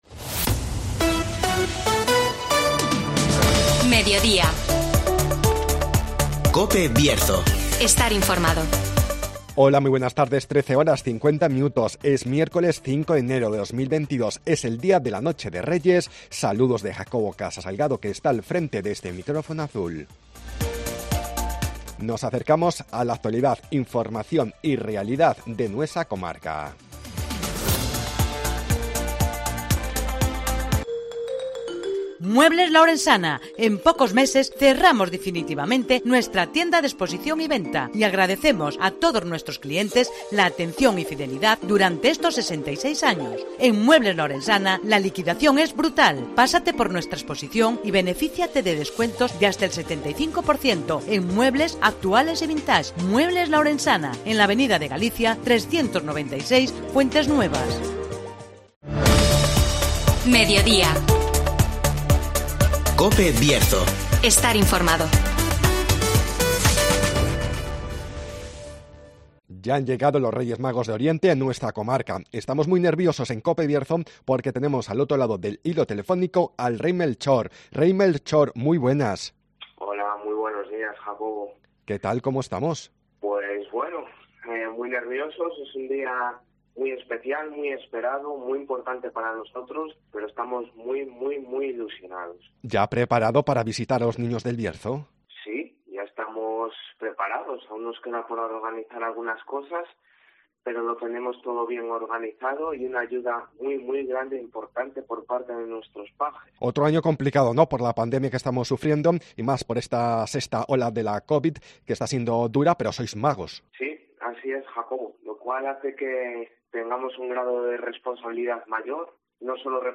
Llegan los Reyes Magos de Oriente al Bierzo (Entrevista al Rey Melchor).